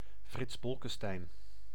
Le nom de Bolkestein fut souvent prononcé à tort à l'allemande, comme dans Frankenstein, alors que la prononciation néerlandaise correcte est « bolkestêïn »
Nl-Frits_Bolkestein.ogg.mp3